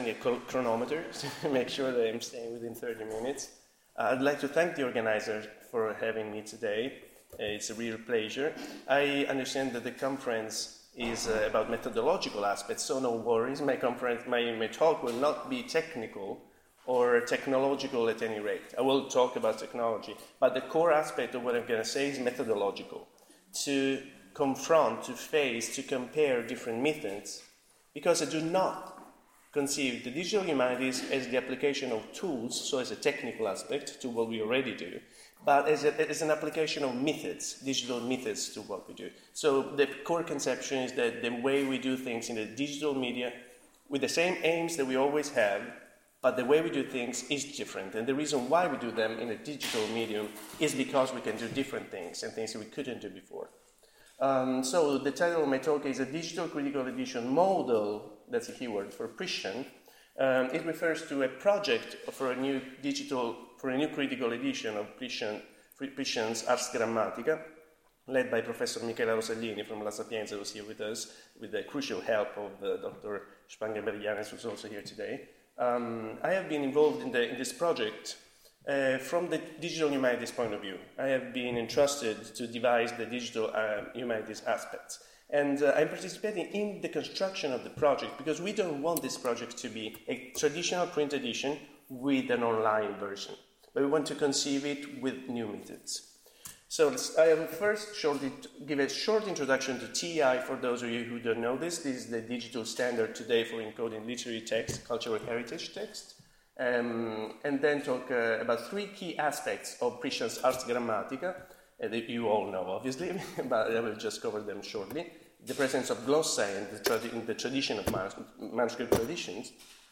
Talk on digital philology and a digital model for an edition of Priscian